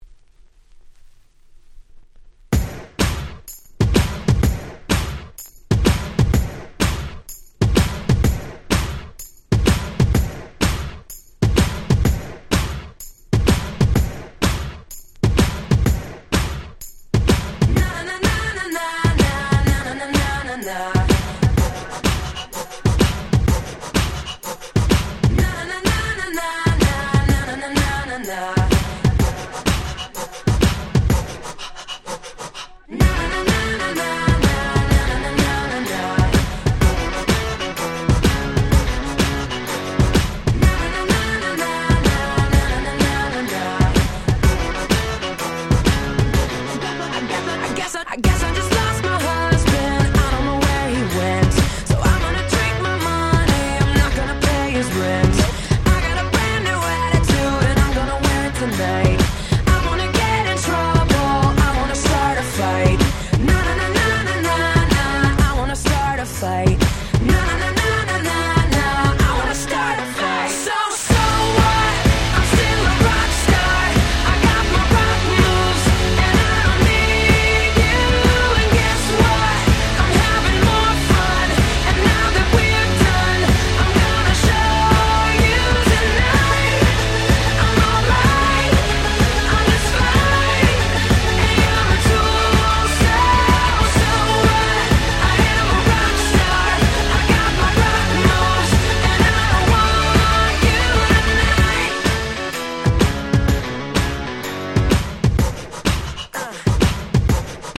09' Super Hit R&B / Pops !!